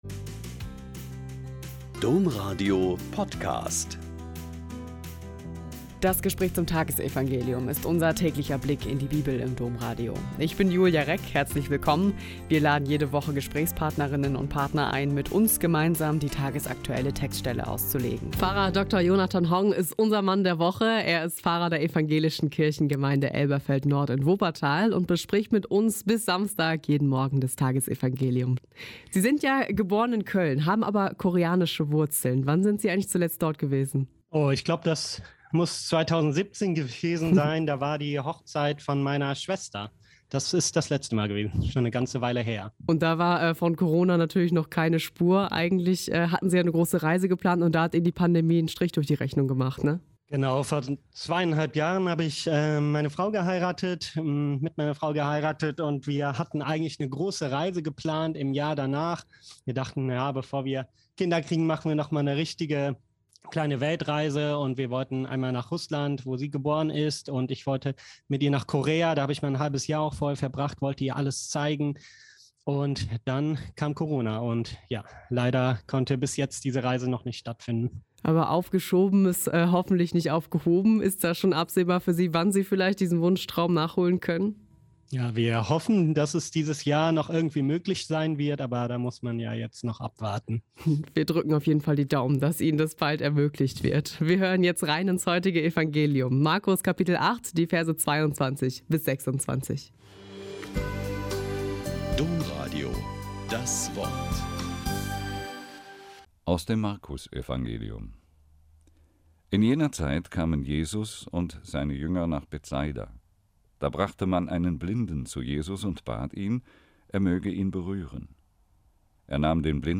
Mk 8,22-26 - Gespräch